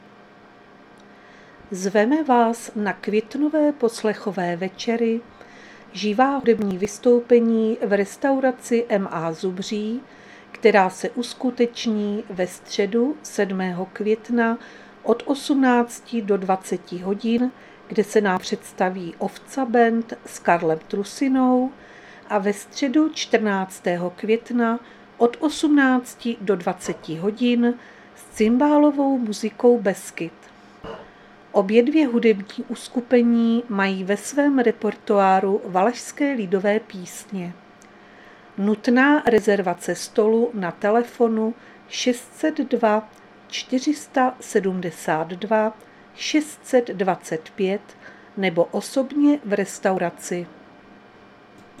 Záznam hlášení místního rozhlasu 5.5.2025